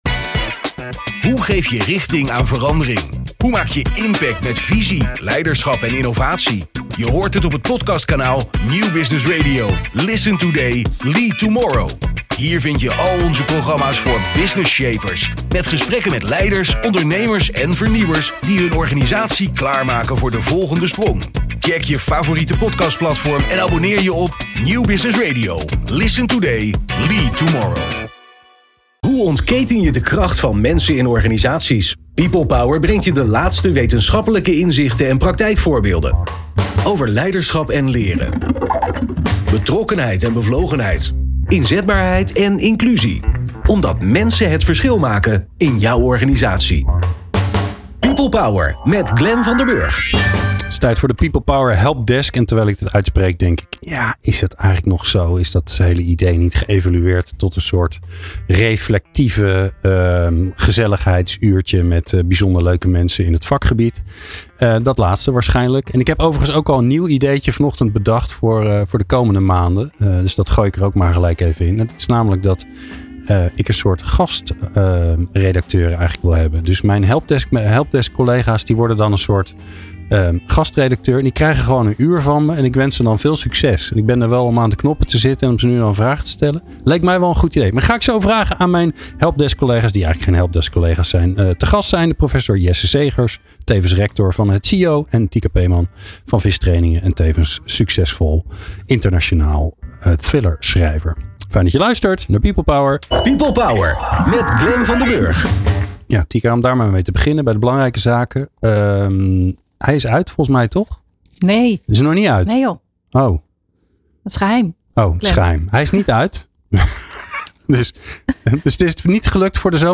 Twee eigenwijze experts geven ongezouten hun ideeën en oplossingen.